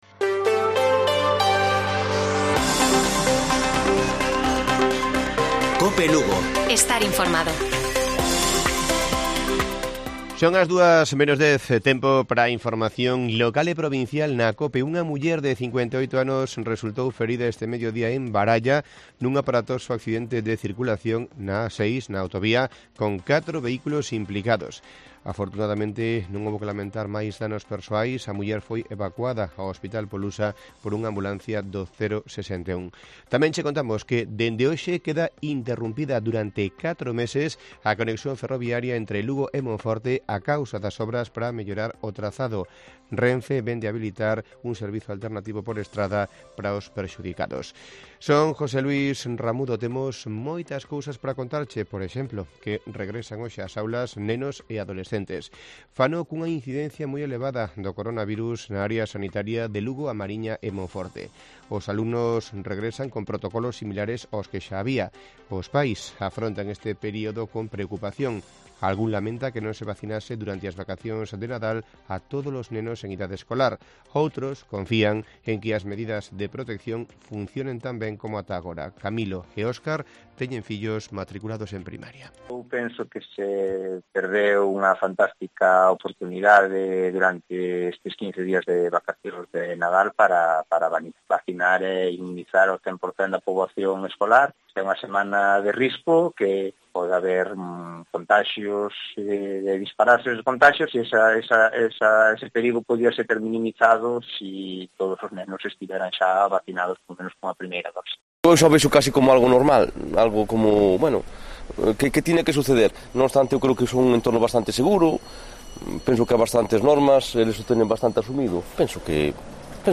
Informativo Mediodía de Cope Lugo. 10 de enero. 13:50 horas